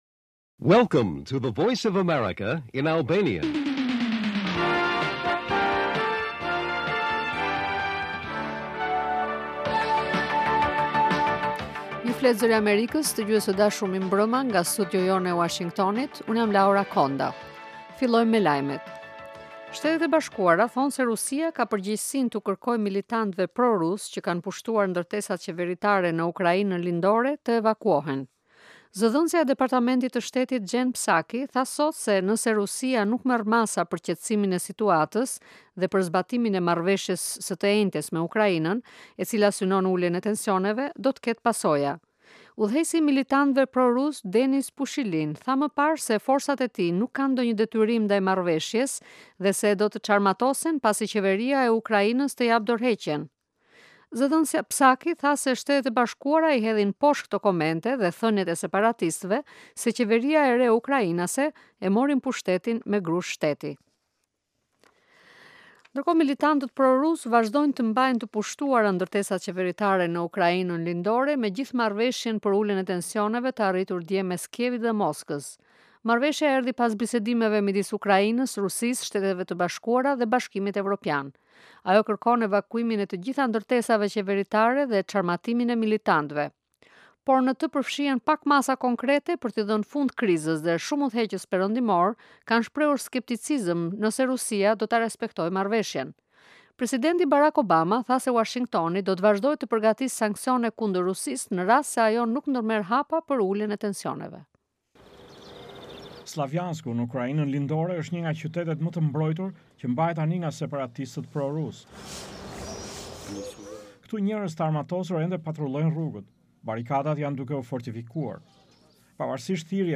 Lajmet e mbrëmjes